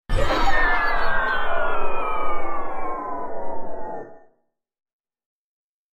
На этой странице собраны звуки резкого отключения электричества – от тихого щелчка выключателя до гула пропадающего напряжения в сети.
Звук угасающего волшебства